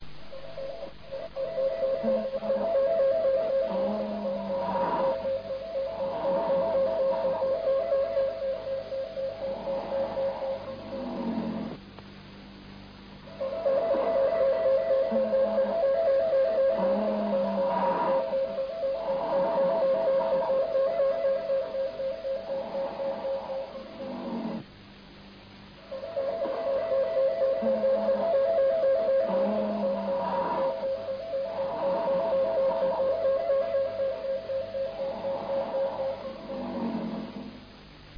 E' possibile ascoltare il suo affaticato battito cardiaco cosi' come venne registrato dai fratelli Judica-Cordiglia.
Il respiro è quello di un uomo, un cosmonauta, ormai privo di sensi e prossimo alla morte.
Heartbeat